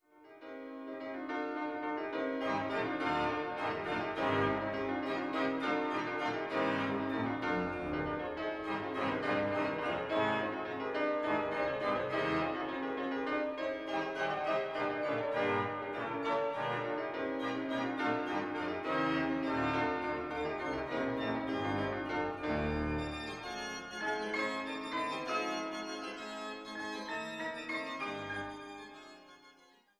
Werke für Orgel und Klavier sowie für Orgel und Orchester